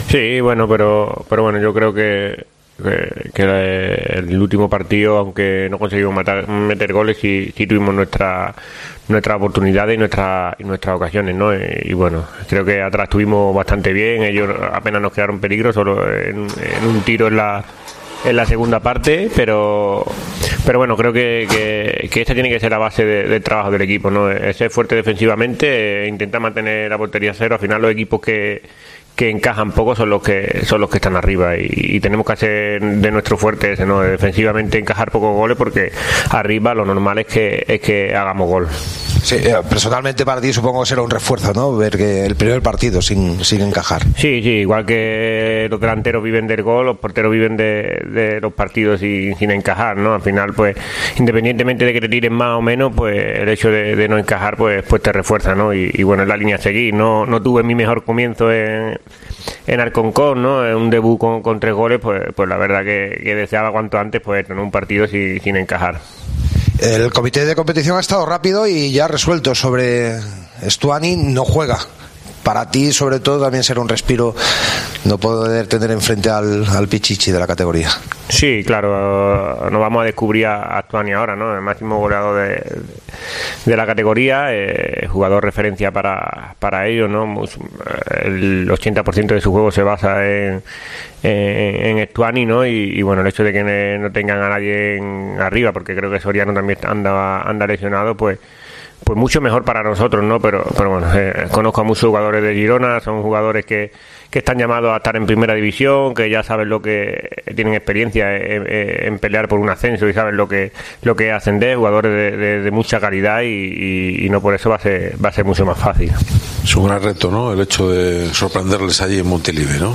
Escucha aquí las palabras de René Román, guardameta de la Deportiva Ponferradina